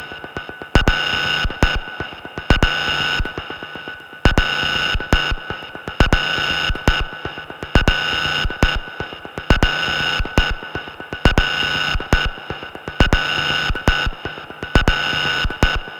VT - Oscyria Perc Loop 11 120bpm.wav